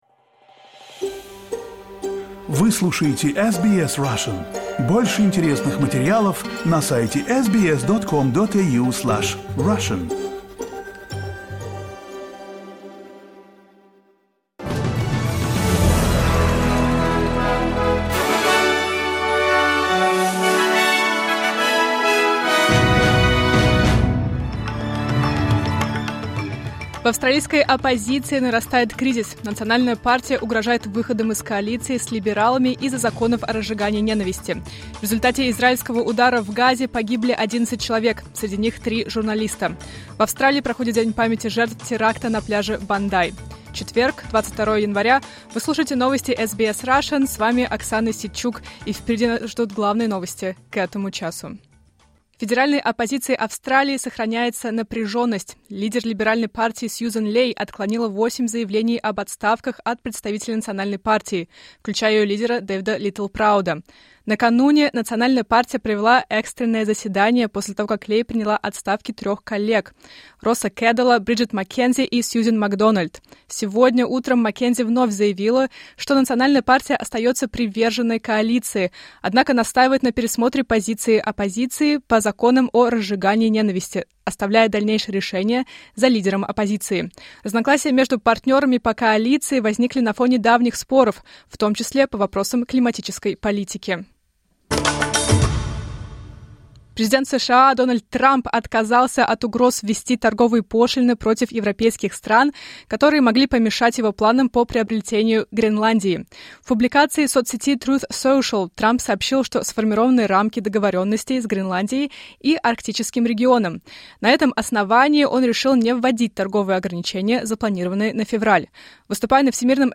Новости SBS на русском языке — 22.01.2026